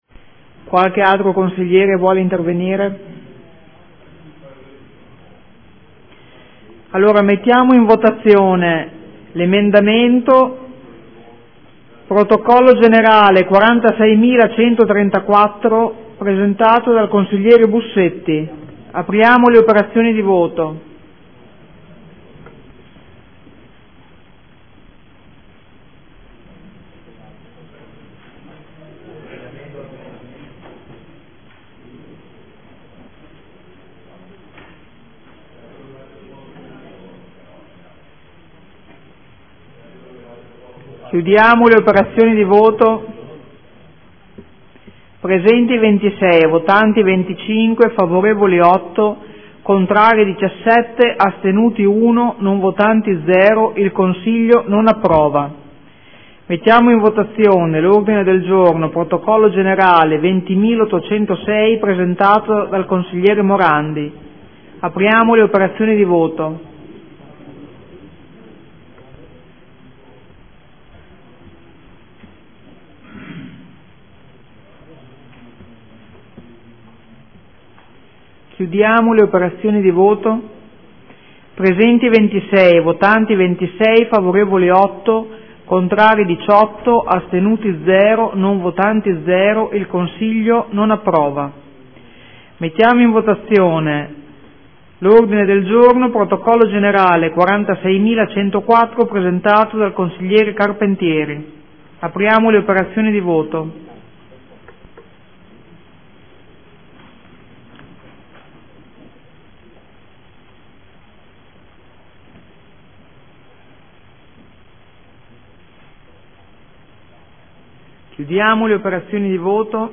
Seduta del 09/04/2015 Mette ai voti emendamento 46134: respinto.
Presidentessa